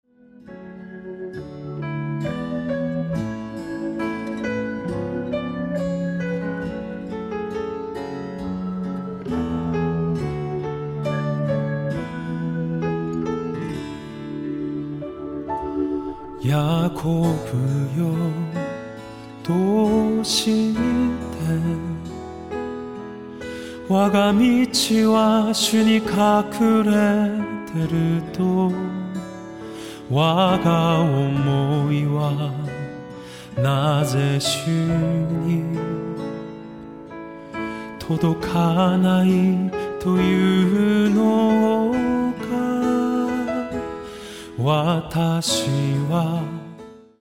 熱く透明な歌声と胸に迫る賛美CD!
日本をベースとして活動する韓国出身のゴスペルシンガー。